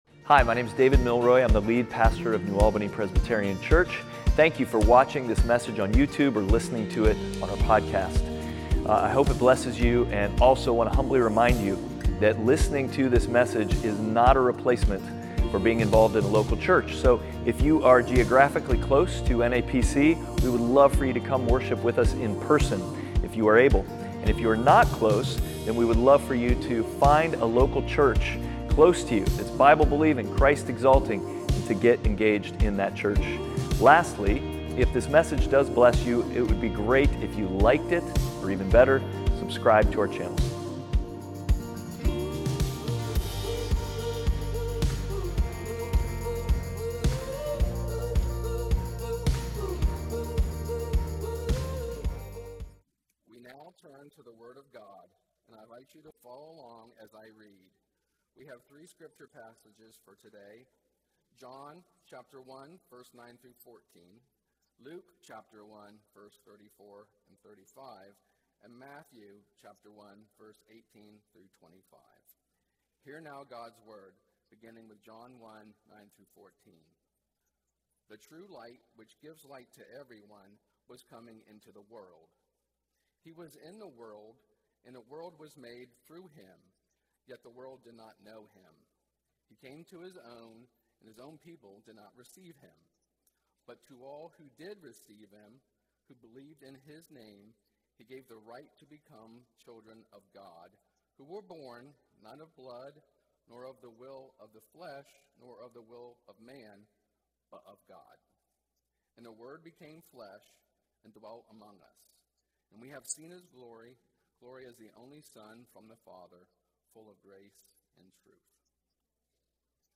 Passage: John 1:9-14, Luke 1:34-35, Matthew 1:18-25 Service Type: Sunday Worship